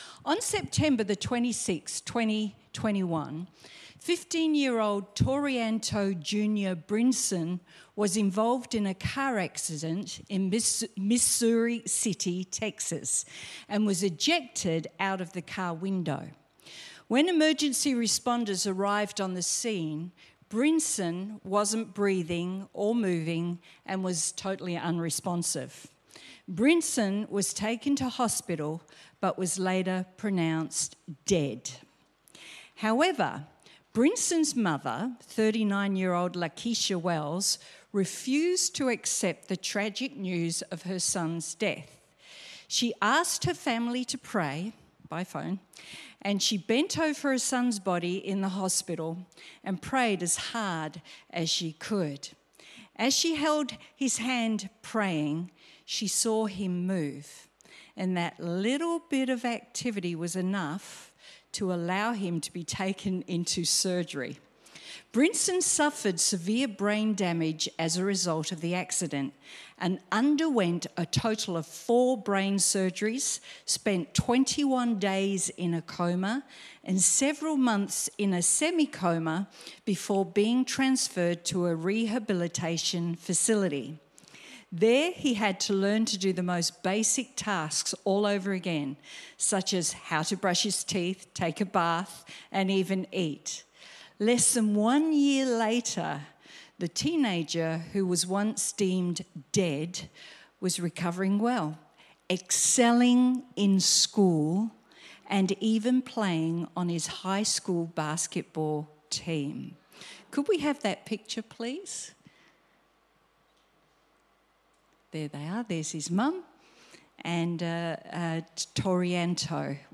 Sermon Transcript On September 26